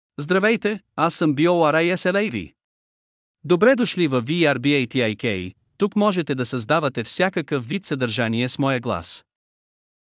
BorislavMale Bulgarian AI voice
Borislav is a male AI voice for Bulgarian (Bulgaria).
Voice sample
Male
Borislav delivers clear pronunciation with authentic Bulgaria Bulgarian intonation, making your content sound professionally produced.